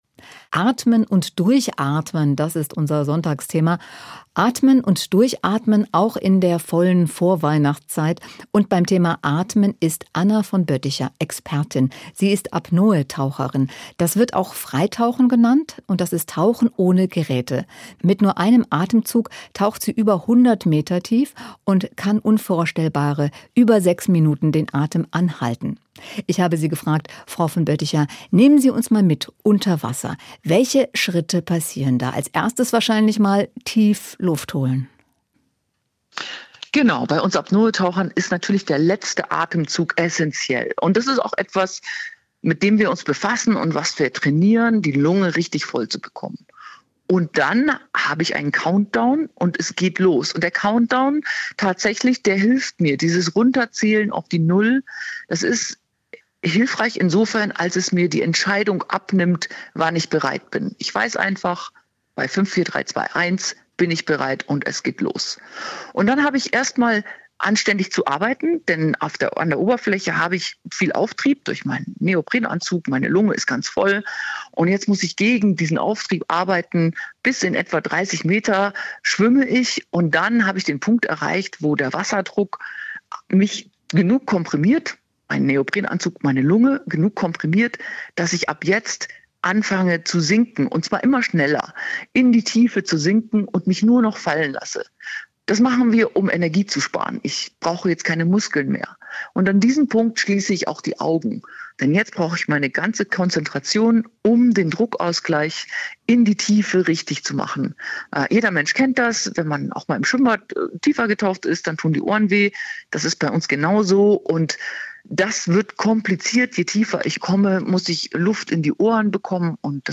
Wenn die Atmung unter Kontrolle und ruhig sei, werde der ganze Körper und das ganze System ruhiger, betonte die Frei-Taucherin im Interview mit SWR1 Sonntagmorgen.